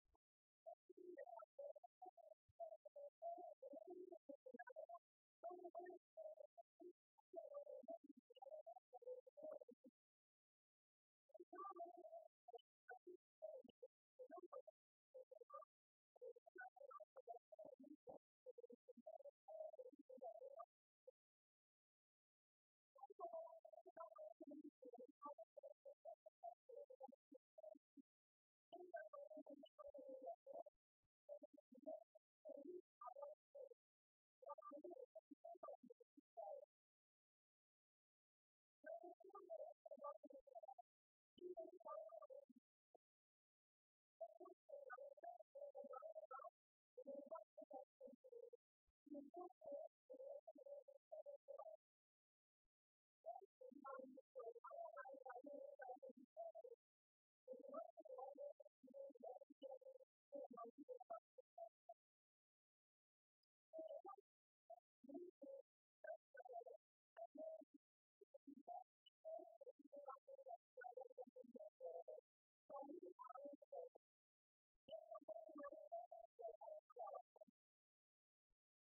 Cérémonie au Sporting
Cérémonies officielles du jumelage
Témoignage